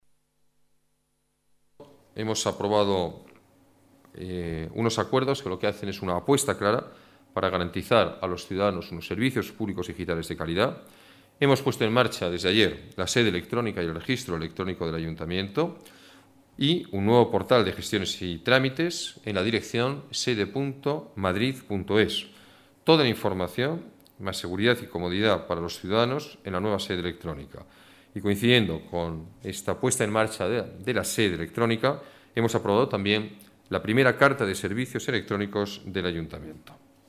Nueva ventana:Declaraciones alcalde, Alberto Ruiz-Gallardón: nueva sede electrónica